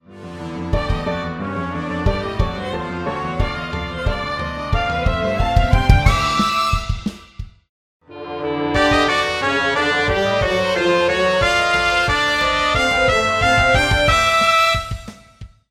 synth brass: ...well it's better than making a "bwaa" sound into a microphone... kind of
yes the synth brass is WAY louder than the recorded one. no i don't know why